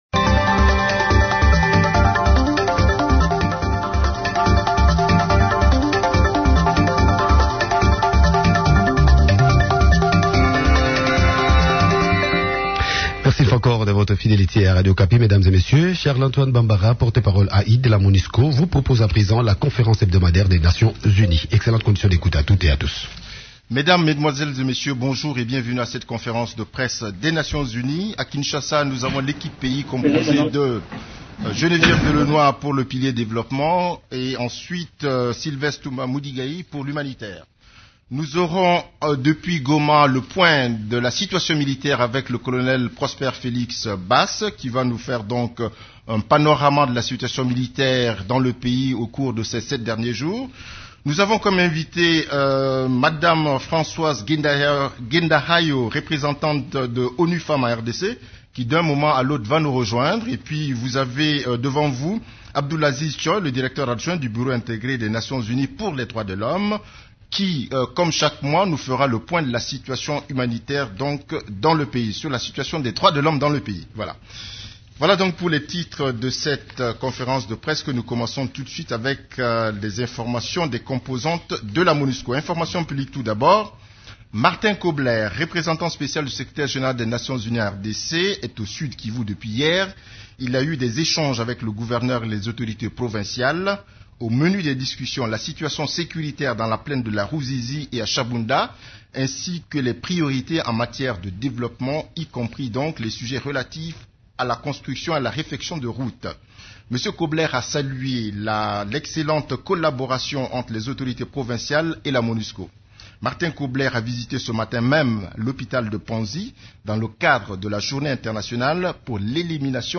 Conférence de presse du 26 novembre 2014
La conférence hebdomadaire des Nations unies du mercredi 26 novembre à Kinshasa a abordé les sujets suivants :